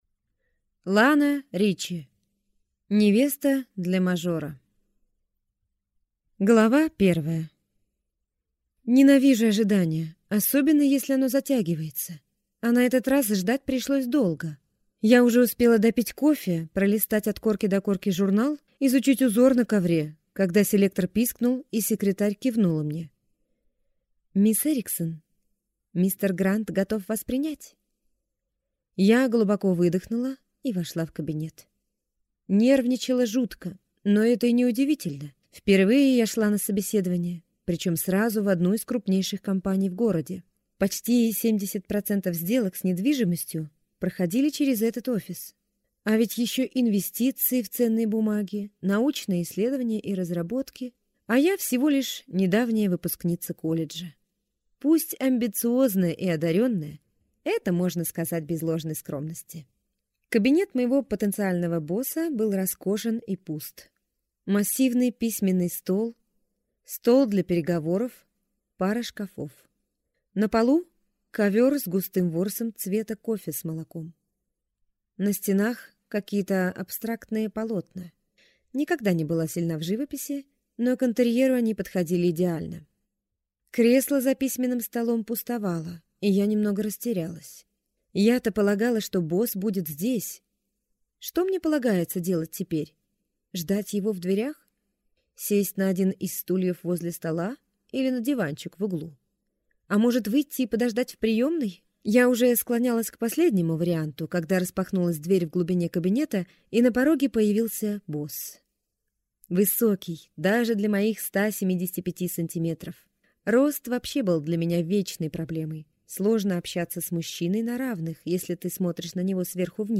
Аудиокнига Невеста для мажора | Библиотека аудиокниг